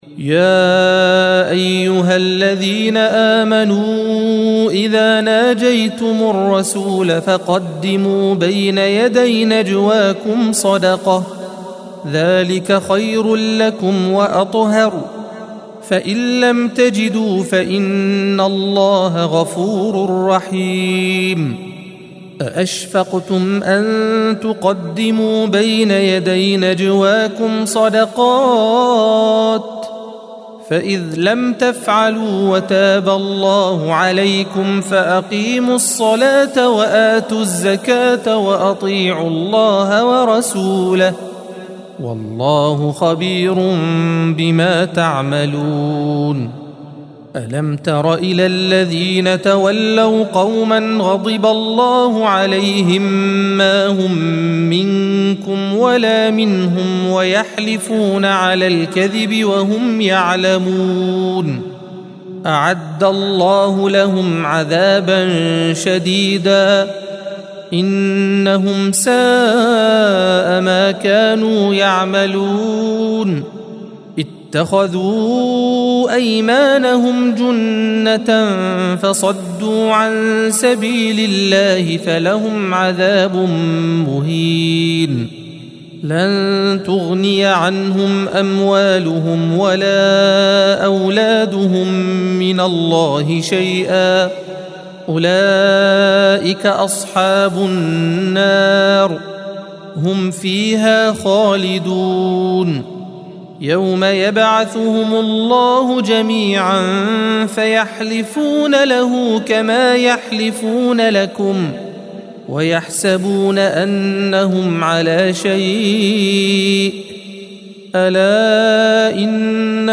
الصفحة 544 - القارئ